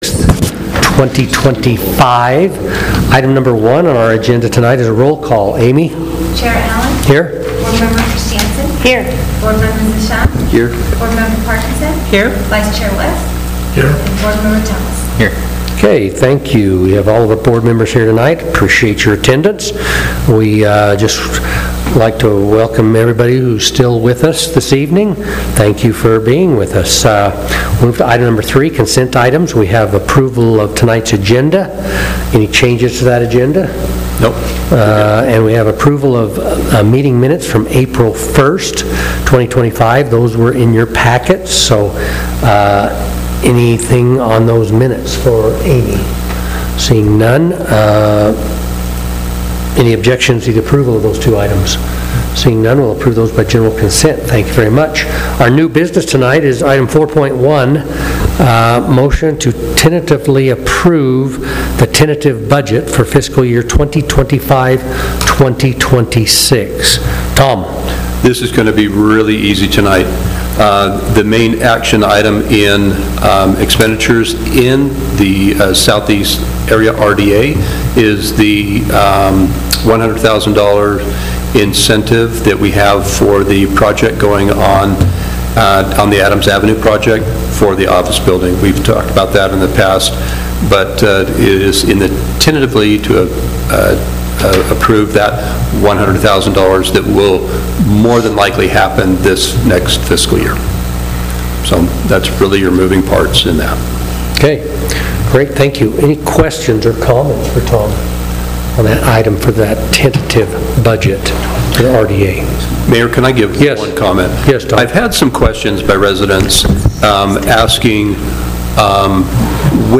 Notice, Meeting